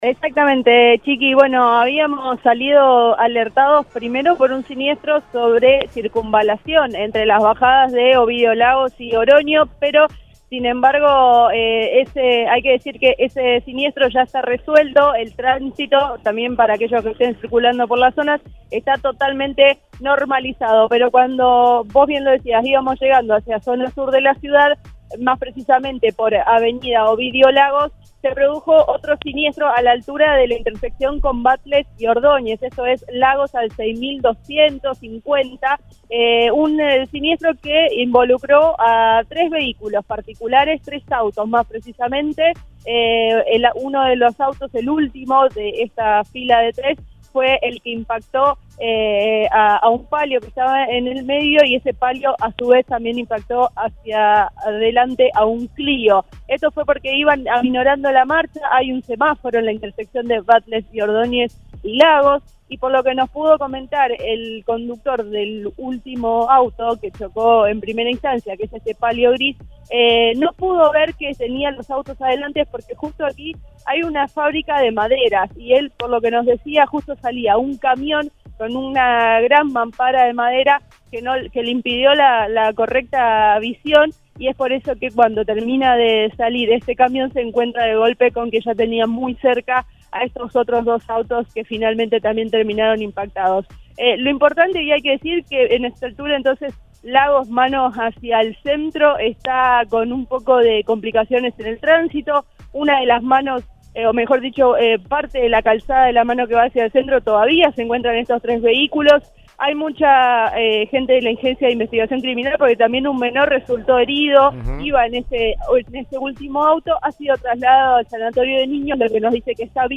Según adujo el conductor del tercer vehículo -a partir del cual presuntamente se desenlazó el siniestro- al móvil de Cadena 3 Rosario, “no pudo ver que tenía los autos adelante, hay una fábrica de maderas, de la cual justo salía un camión con mampara que le impidió la correcta visión”.